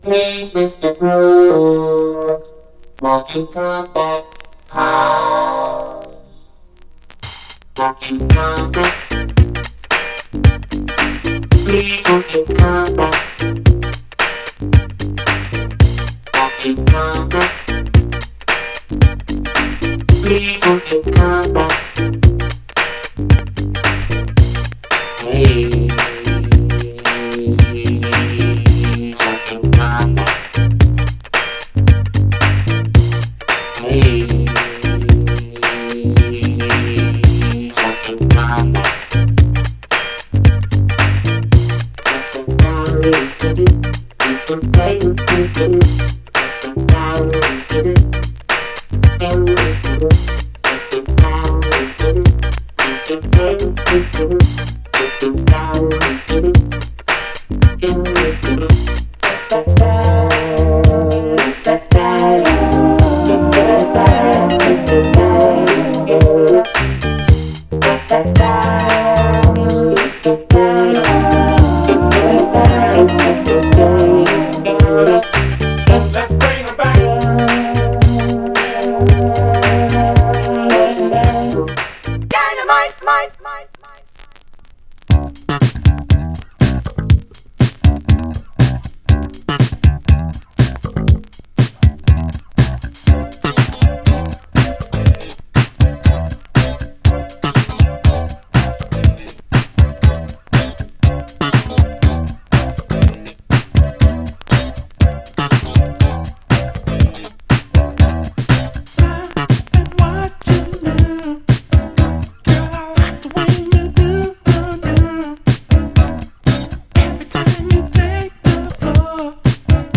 チョッパー・ベースとシンセのグルーヴが疾走感を生む高品位モダン・ブギー
盤はエッジ中心に細かいヘアーラインキズ、ごく細かい小キズ箇所、B面エッジにプレスゴミ箇所あり、序盤でチリつきます。
FORMAT 7"
※試聴音源は実際にお送りする商品から録音したものです※